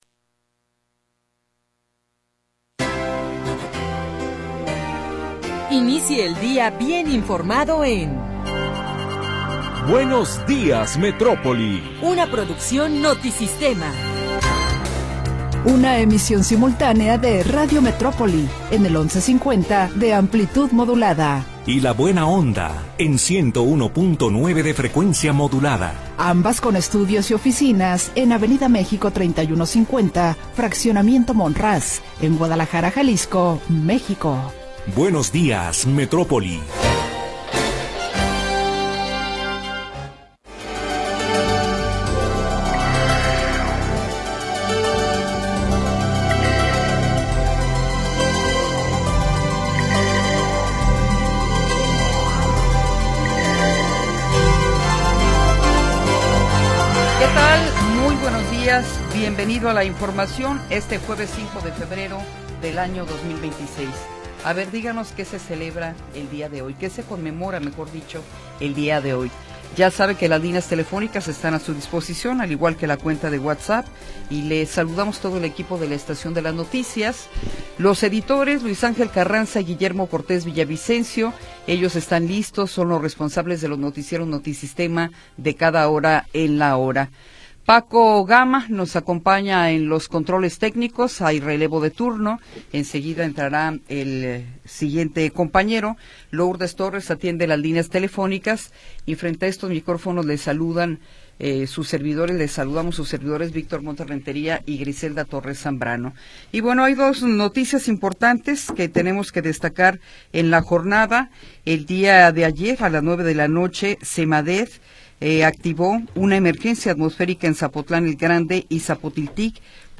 Primera hora del programa transmitido el 5 de Febrero de 2026.